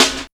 99 SNARE 2.wav